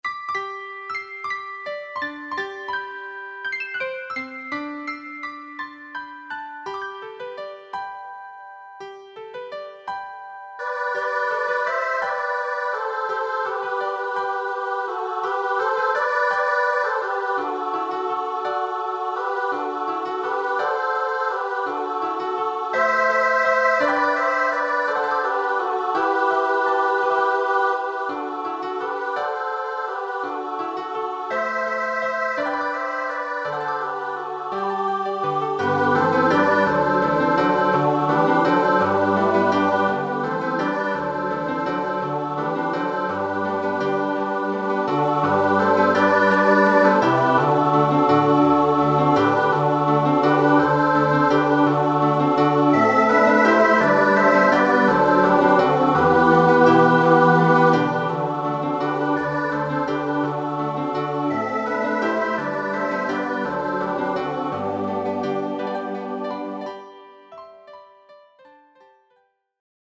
with English text
SATB choral arrangement
traditional Italian Christmas carol
SATB choir and piano.